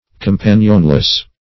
Companionless \Com*pan"ion*less\, a. Without a companion.